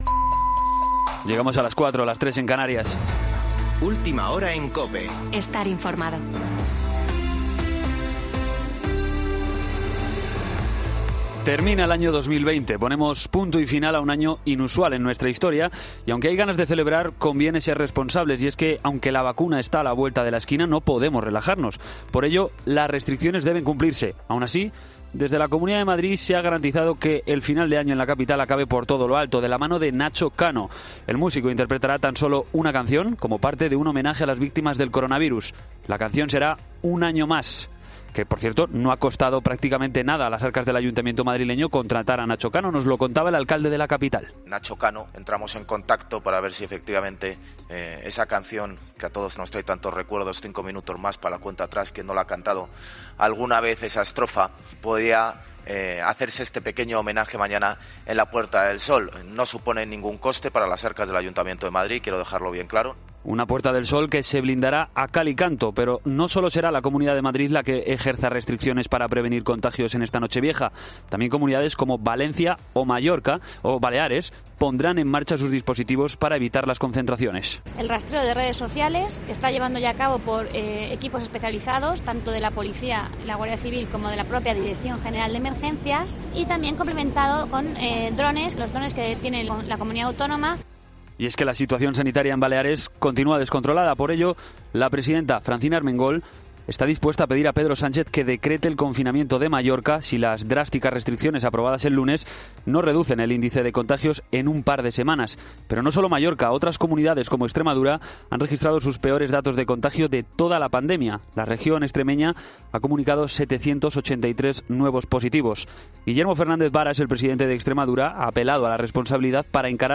Boletín de noticias COPE del 31 de diciembre de 2020 a las 04.00 horas